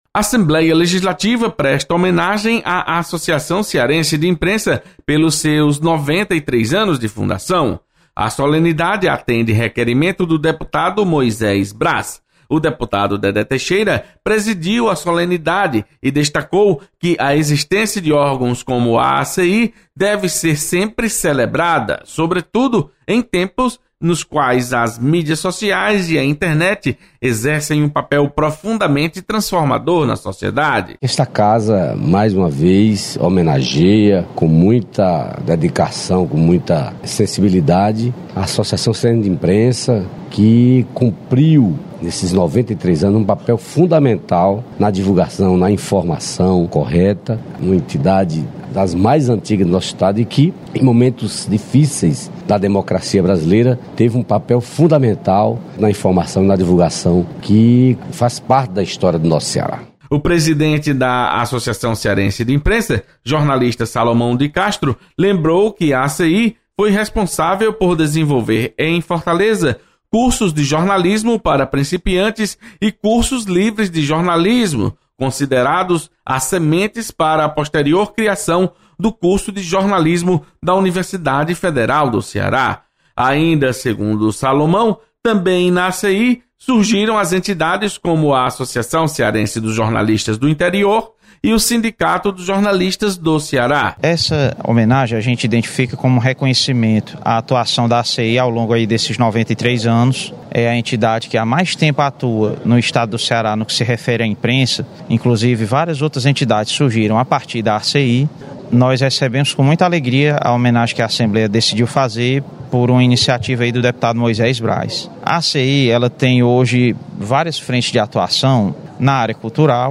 Assembleia presta homenagem à Associação Cearense de Imprensa. Repórter